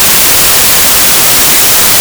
Audio abspielen (Geräusch)
Jede nicht-periodische Schallempfindung wird als Geräusch oder Rauschen (engl. noise) bezeichnet.
Noise.ogg